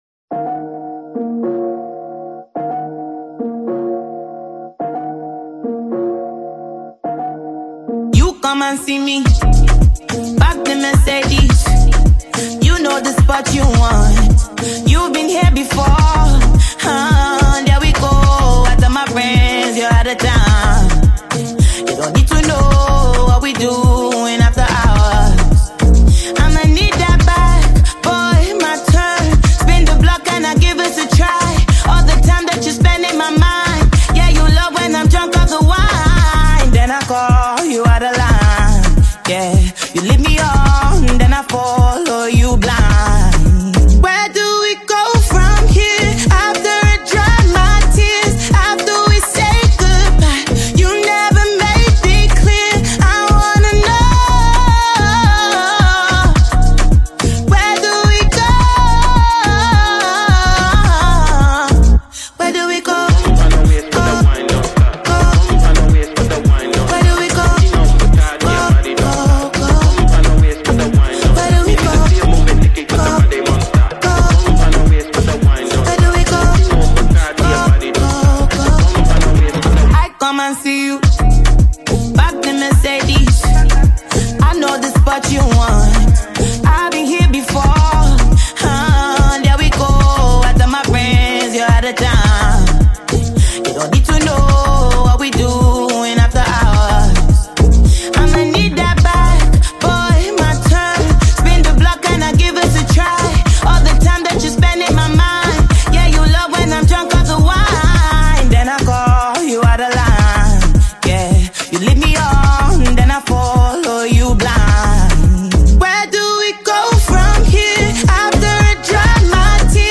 smooth melodies with reflective lyrics
With its heartfelt message and polished sound